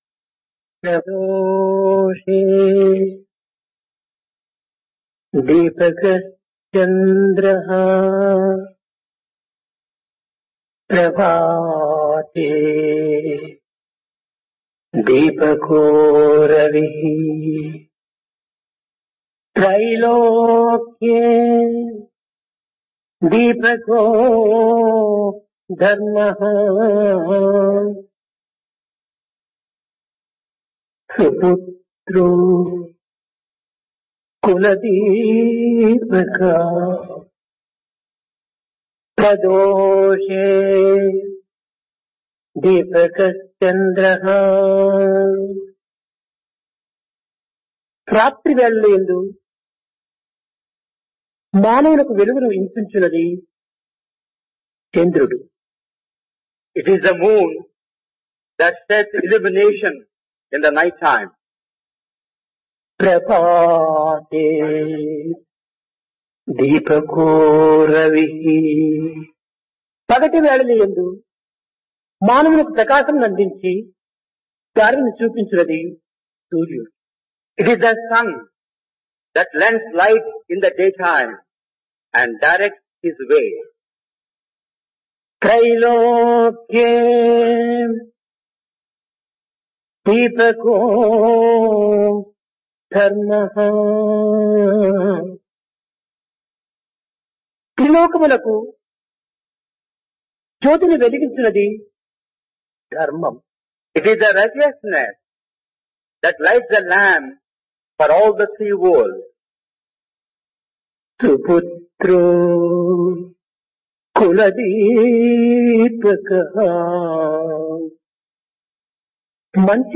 Discourse
Place Prasanthi Nilayam